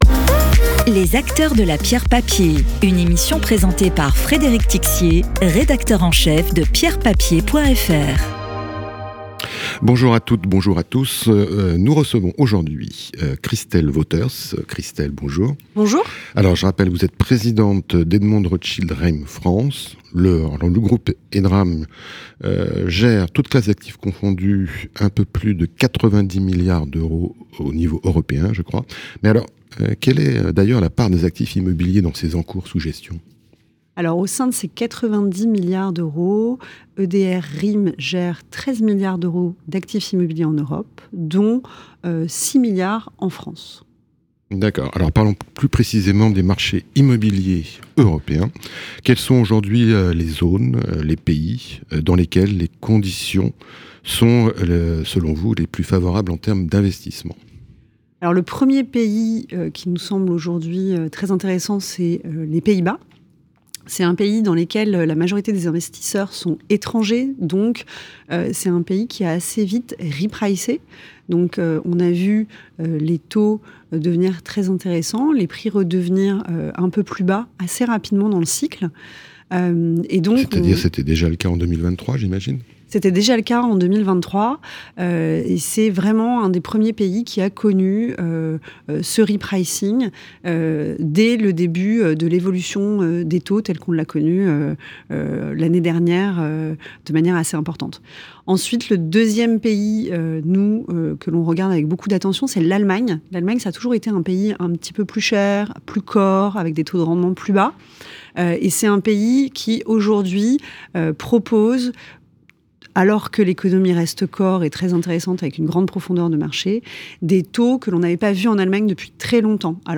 Podcast d'expert
Interview.